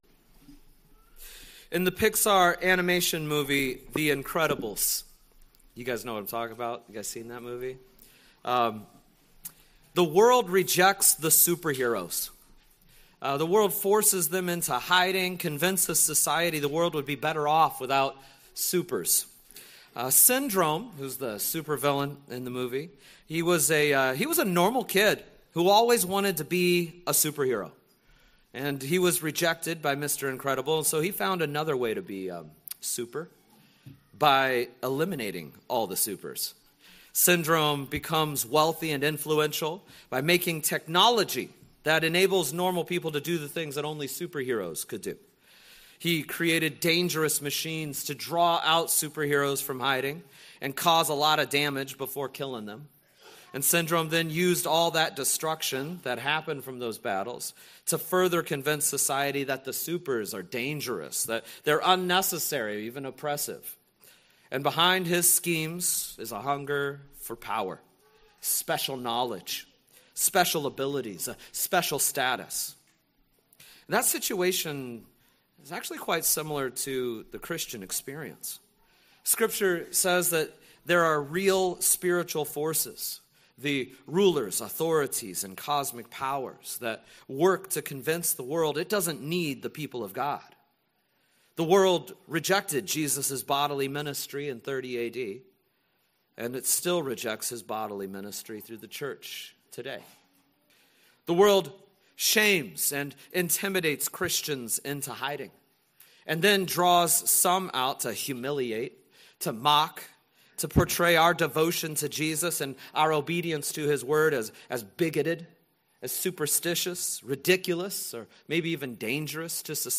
In this sermon from Colossians